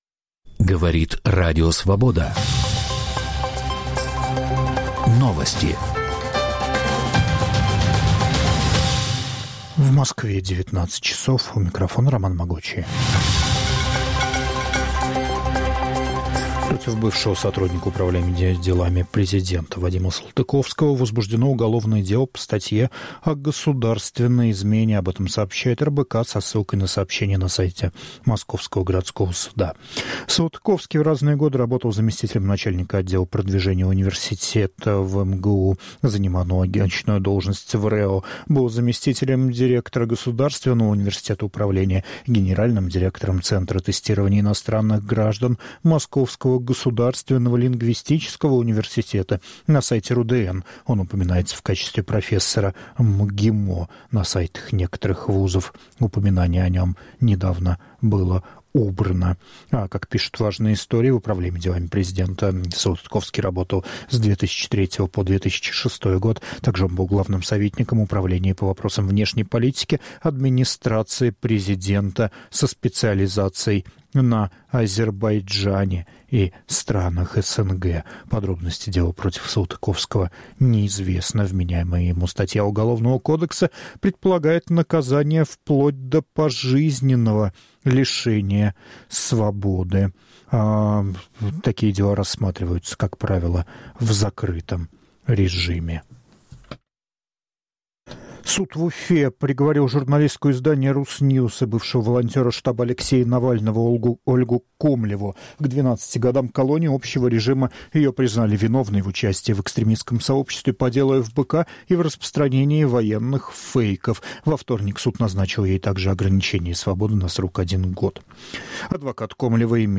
Аудионовости